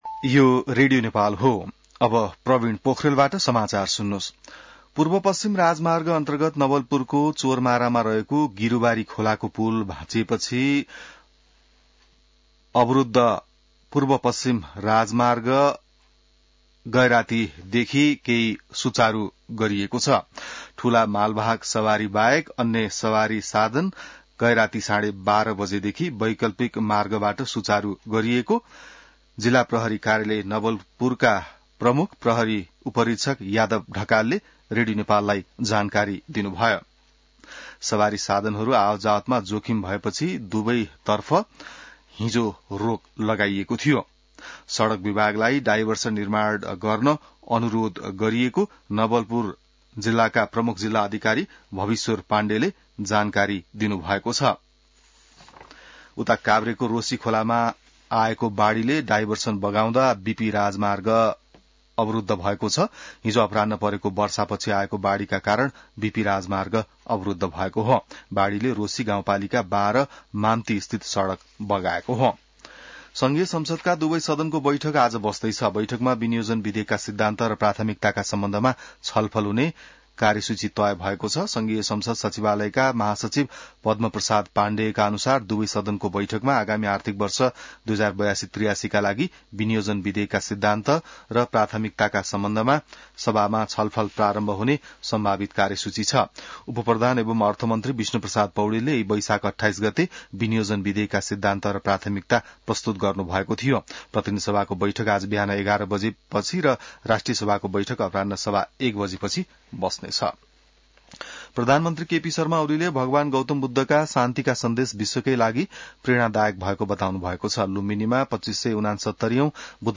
बिहान ६ बजेको नेपाली समाचार : ३० वैशाख , २०८२